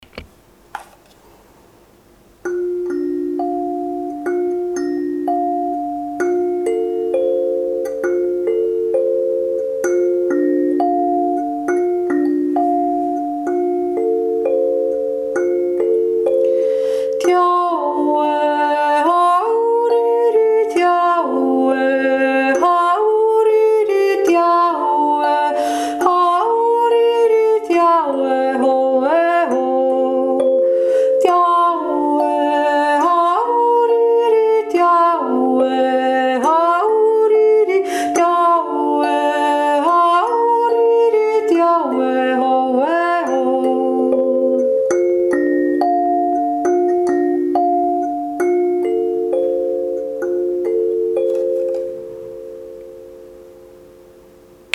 Die Jodler
1. Stimme